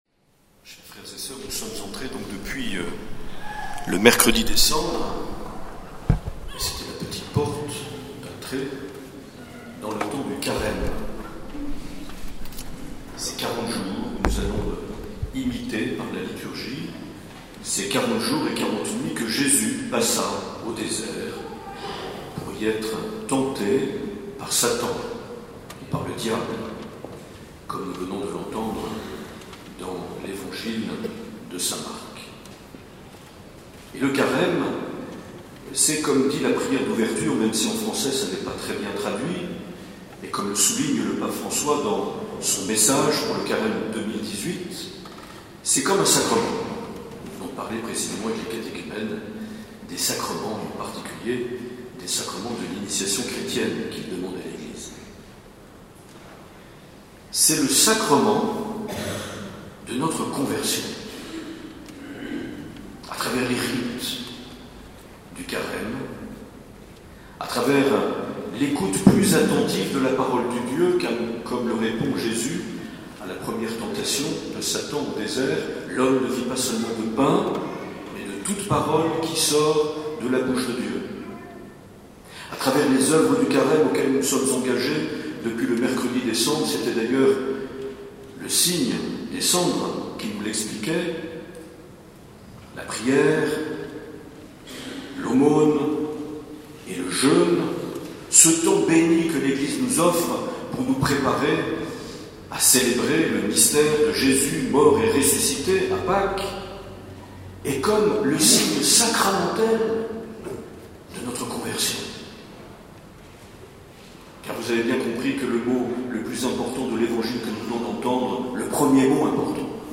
18 février 2018 - Salies-de-Béarn - Appels décisifs des catéchumènes
Les Homélies
Une émission présentée par Monseigneur Marc Aillet